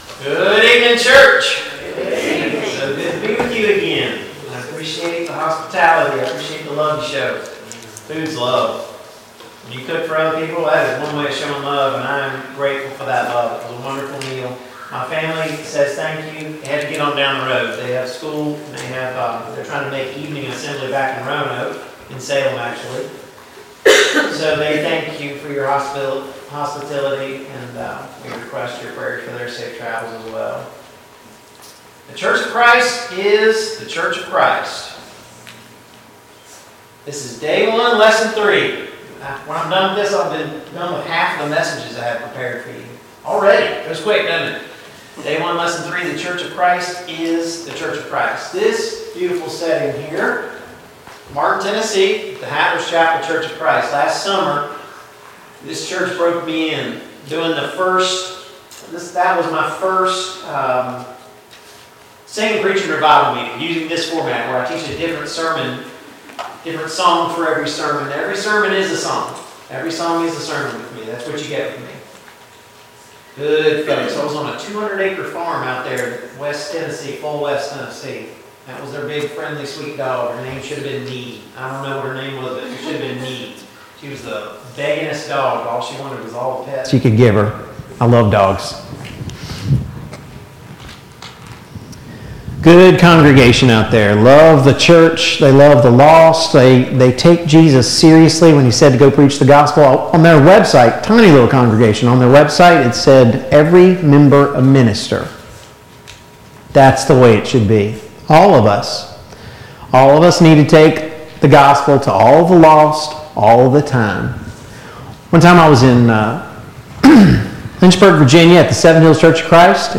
2022 Spring Gospel Meeting Service Type: Gospel Meeting Download Files Notes « 2.